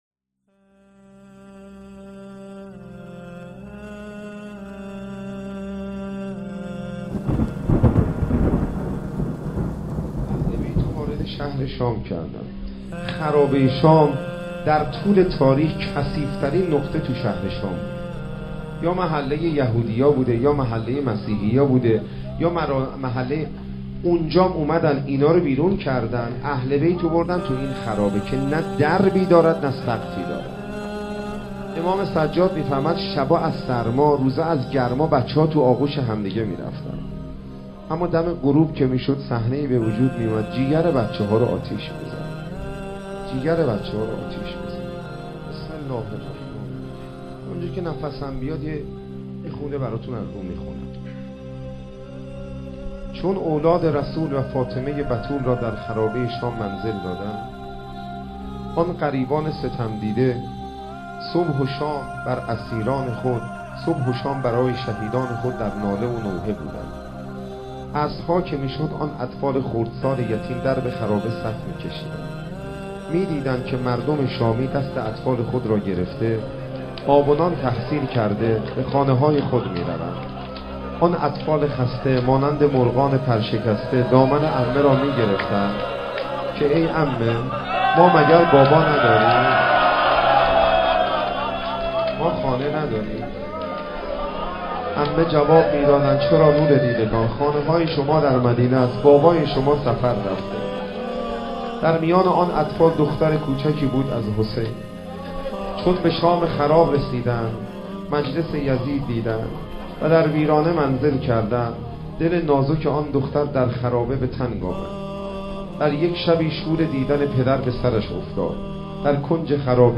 سخنرانی و روضه خوانی - شهادت حضرت رقیه سلام الله علیها